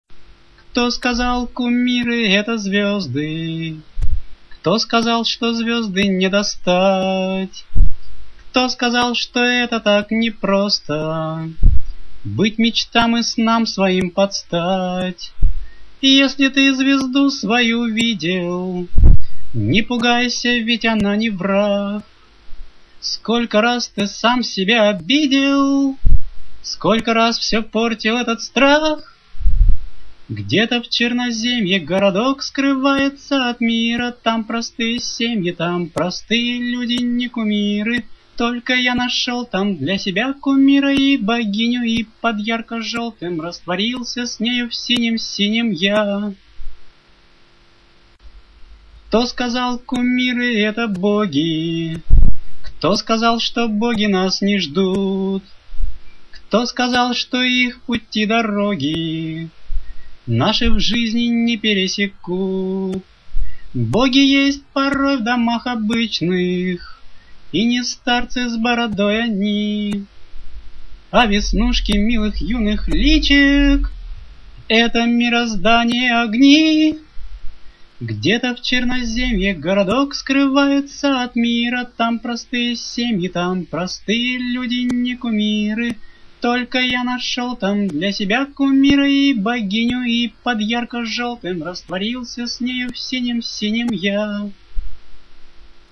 Песня
Прослушать в авторском исполнении (только вокал):